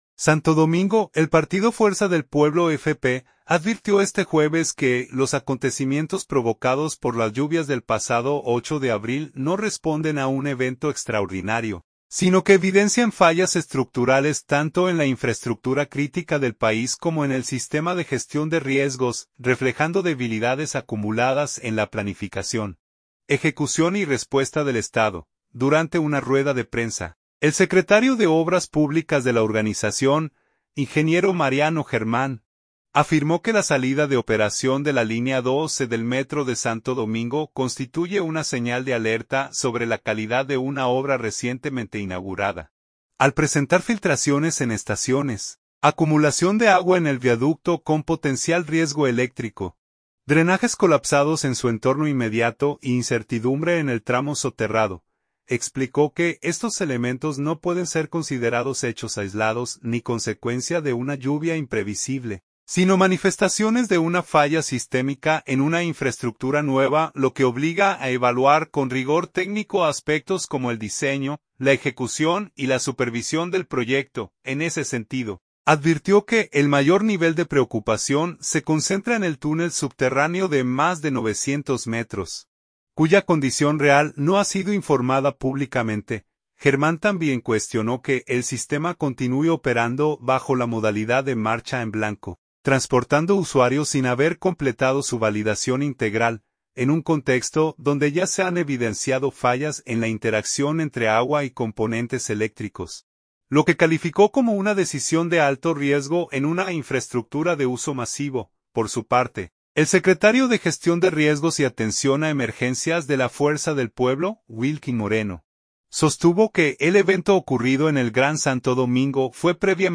Durante una rueda de prensa